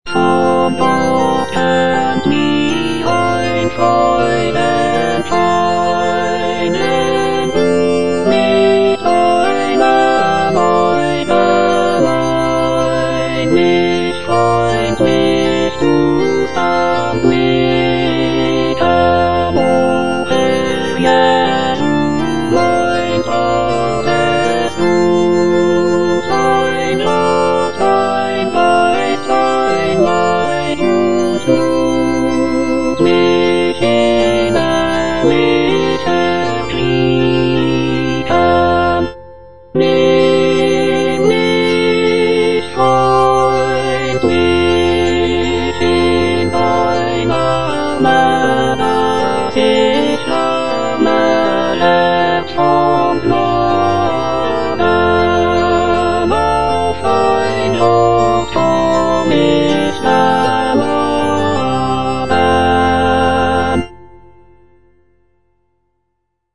J.S. BACH - CANTATA "ERSCHALLET, IHR LIEDER" BWV172 (EDITION 2) Von Gott kömmt mir ein Freudenschein - Alto (Emphasised voice and other voices) Ads stop: auto-stop
The text celebrates the coming of the Holy Spirit and the birth of the Christian Church. The music is characterized by its lively rhythms, rich harmonies, and intricate counterpoint.